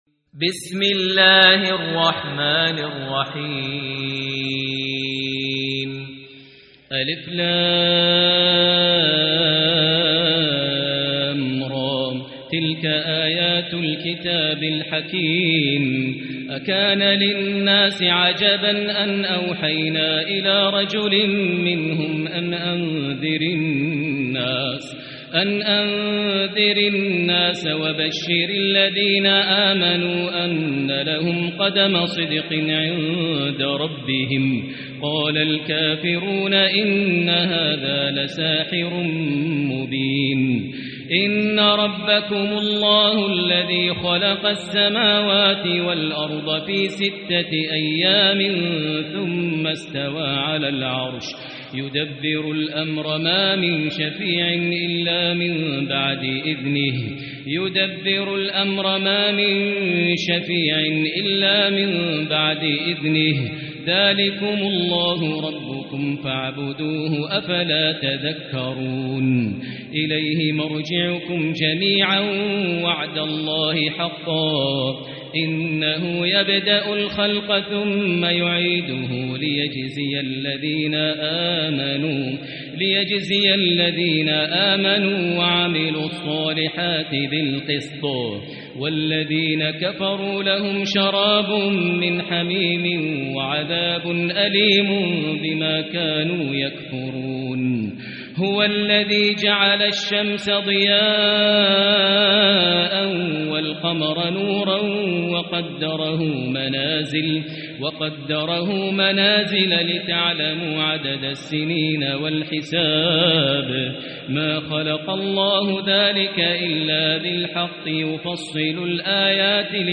سورة يونس | مصحف الحرم المكي ١٤٤٤ > مصحف تراويح الحرم المكي عام ١٤٤٤ > المصحف - تلاوات الحرمين